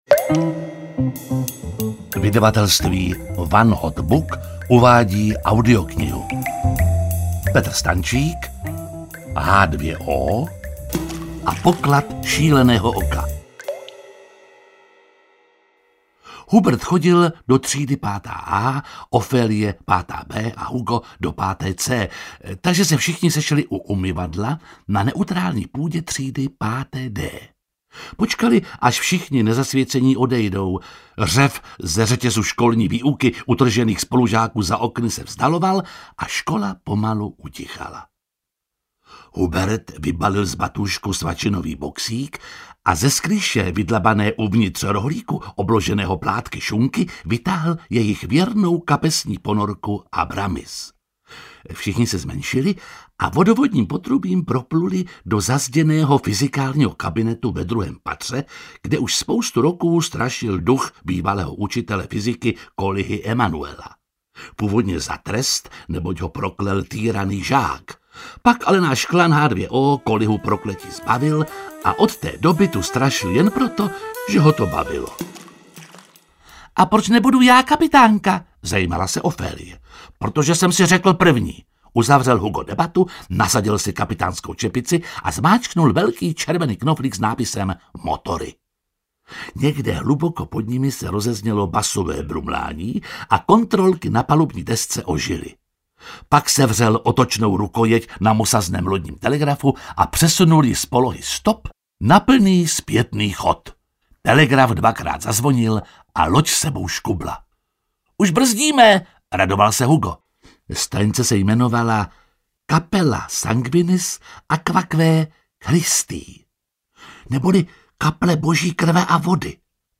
Ukázka z knihy
• InterpretJiří Lábus